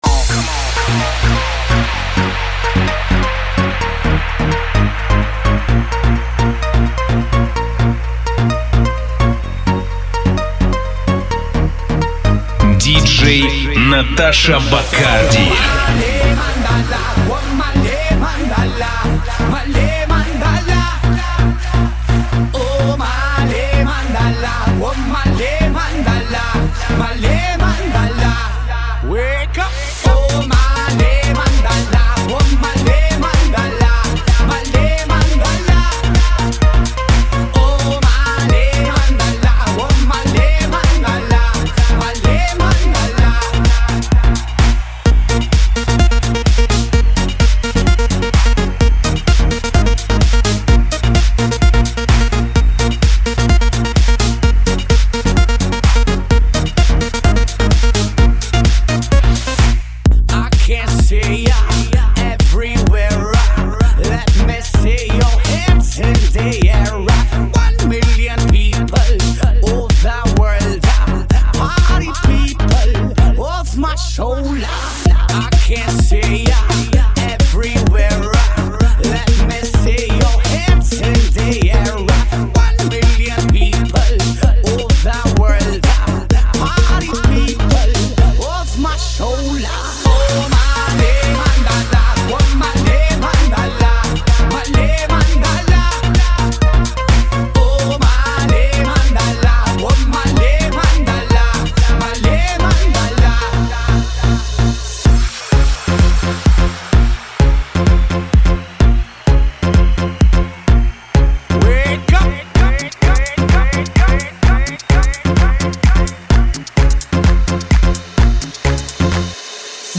Категория: Club & Dance